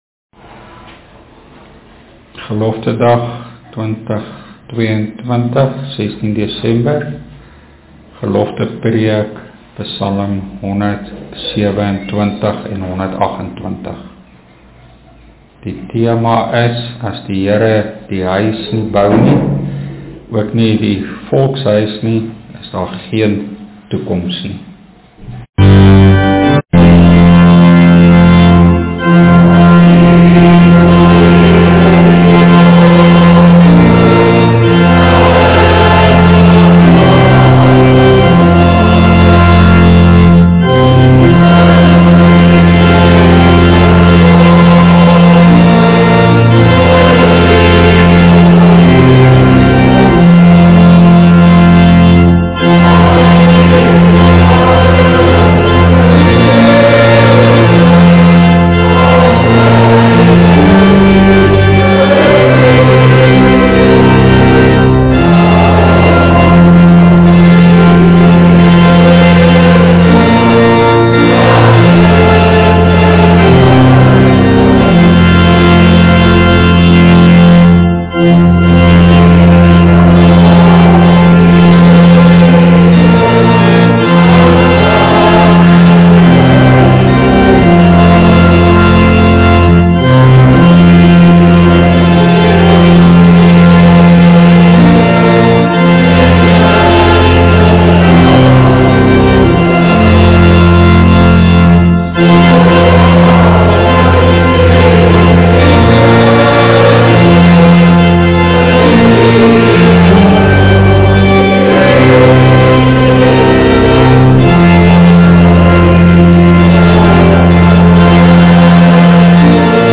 GELOFTE VEROOTMOEDIGINGSPREEK 2022
pslc-ps.-127-geloftepreek.mp3